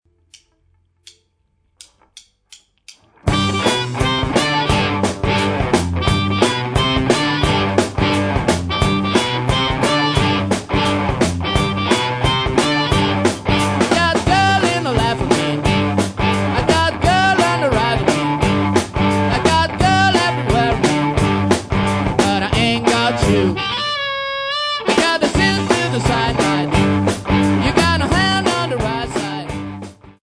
Estratti di brani registrati in sala prove